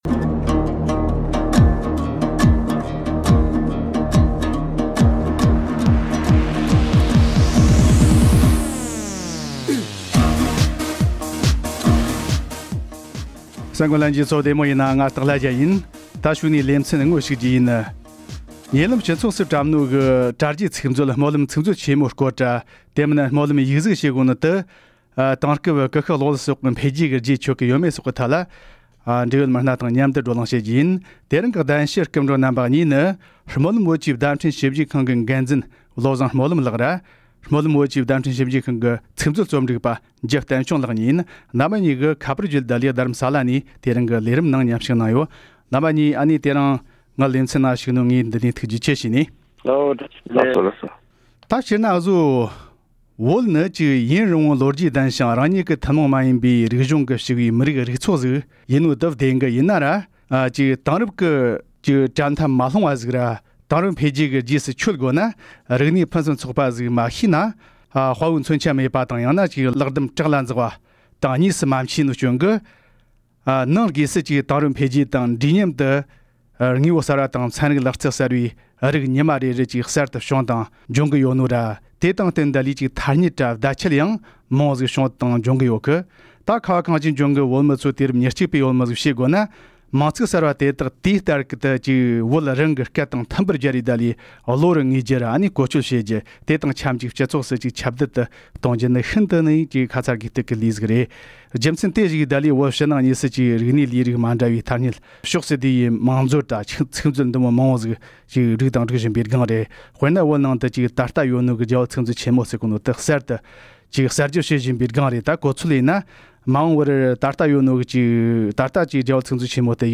༄༅། །ཉེ་ལམ་སྤྱི་ཚོགས་སུ་བཀྲམས་པའི་དྲྭ་རྒྱའི་ཚིག་མཛོད ༼སྨོན་ལམ་ཚིག་མཛོད་ཆེན་མོའི༽ སྐོར་དང་། དེ་མིན་སྨོན་ལམ་ཡིག་གཟུགས་ཞེས་པ་དེ་དེང་སྐབས་ཀུ་ཤུ་གློག་ཀླད་སོགས་ཀྱི་འཕེལ་རྒྱས་ཀྱི་རྗེས་ཆོད་ཀྱིན་ཡོད་མེད་སོགས་ཀྱི་ཐད་འབྲེལ་ཡོད་མི་སྣ་དང་མཉམ་དུ་བགྲོ་གླེང་ཞུ་རྒྱུ་ཡིན།